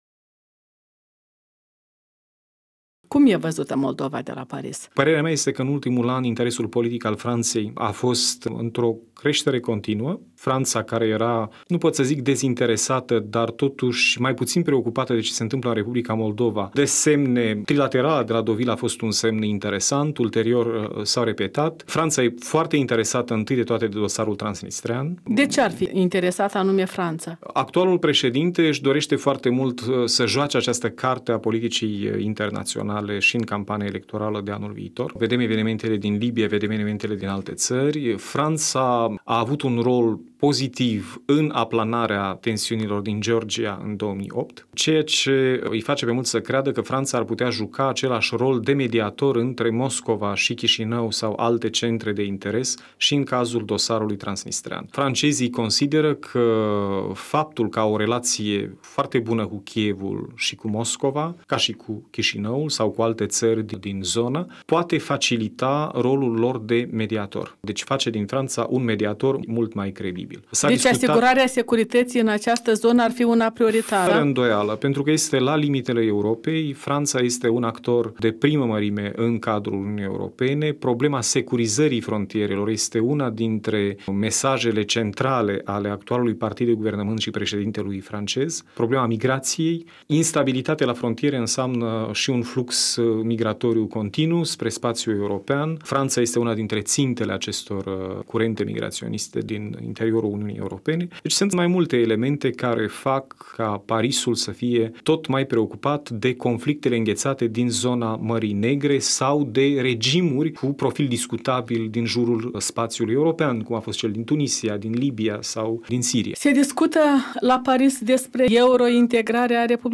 Un interviu cu ambasadorul Republicii Moldova la Paris, Oleg Serebrian